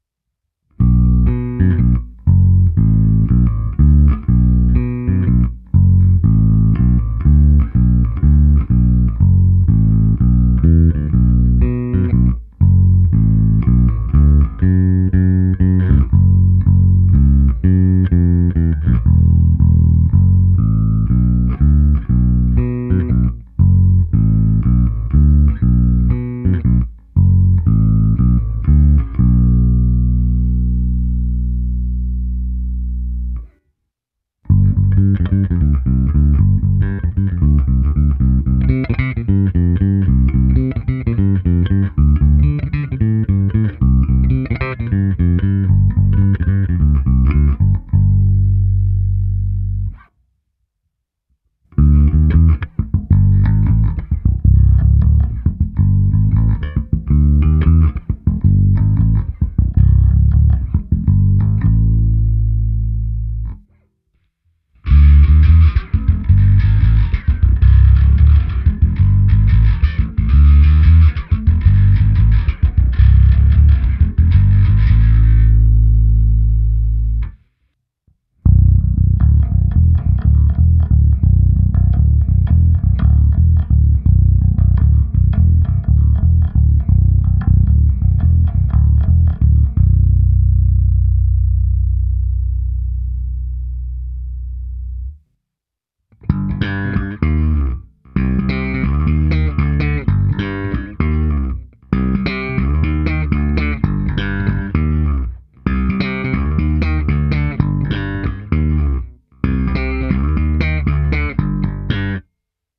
Krásně artikulovaná je i struna H. Zvuk je díky možnému vzájemnému namíchání poměru snímačů variabilní, ale řekl bych, že nejvíce je použitelný zvuk na oba snímače.
Není-li uvedeno jinak, následující nahrávky jsou provedeny rovnou do zvukové karty.
Ukázka struny H